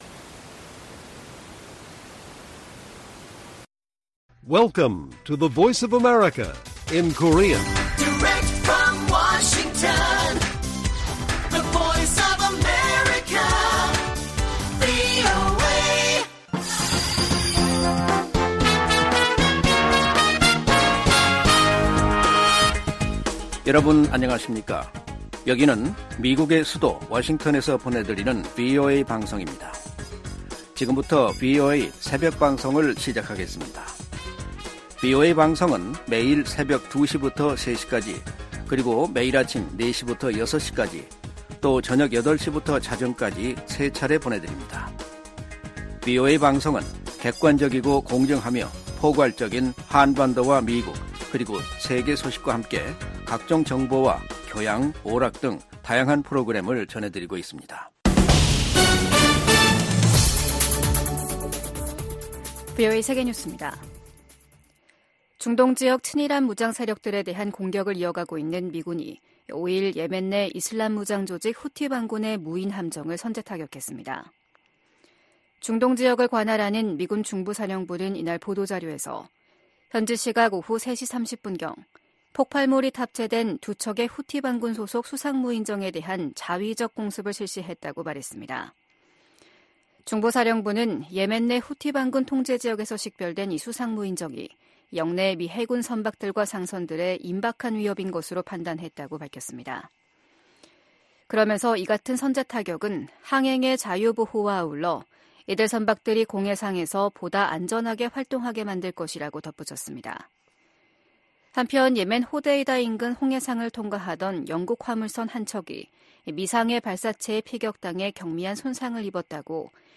VOA 한국어 '출발 뉴스 쇼', 2024년 2월 7일 방송입니다. 북한 핵 정책을 비판한 윤석열 한국 대통령에 대해 러시아 외무부 대변인이 비판 논평을 내면서 갈등이 악화될 조짐을 보이고 있습니다. 미국 정부는 북한의 미사일 경보 정보를 한국· 일본과 계속 공유할 것이라고 밝혔습니다. 오는 11월 도널드 트럼프 전 대통령이 당선되면 임기 초 북한과 협상할 가능성이 있다고 존 볼튼 전 국가안보보좌관이 VOA 인터뷰에서 전망했습니다.